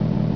engine4.wav